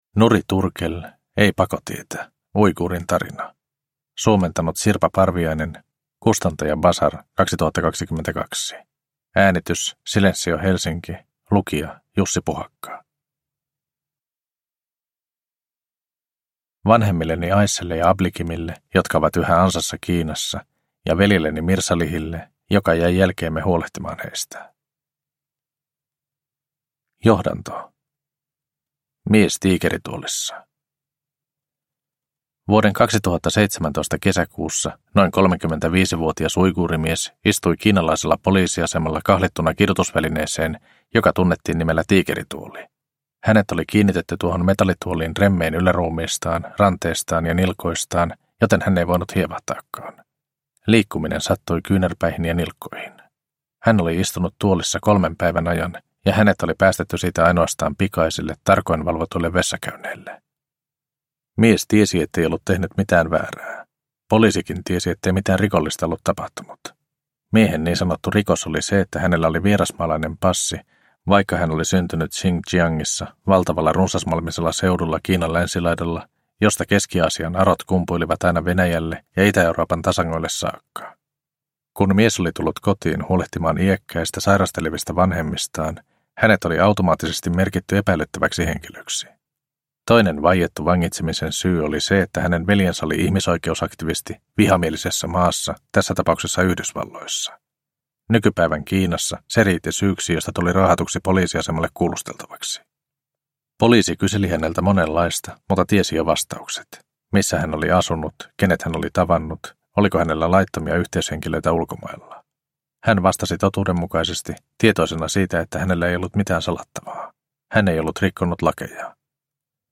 Ei pakotietä – Uiguurin tarina – Ljudbok – Laddas ner